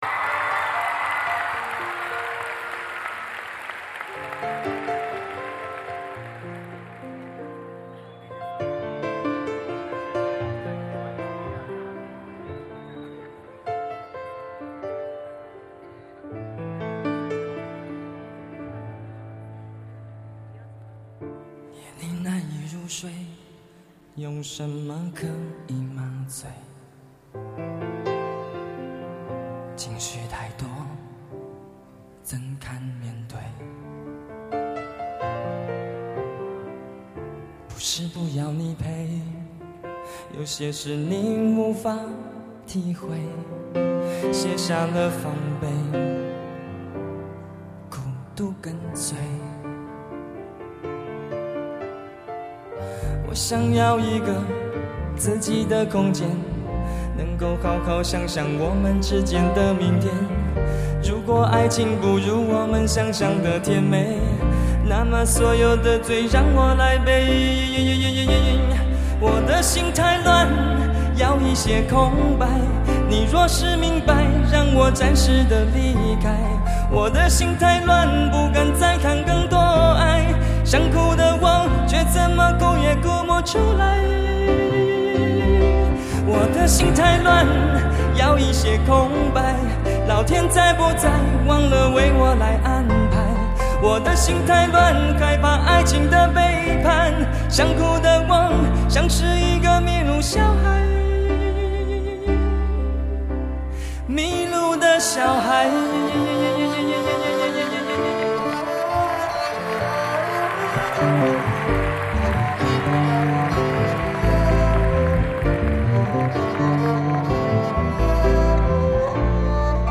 耳熟能详的情歌，台下观众的合唱，浪漫暖随处可见！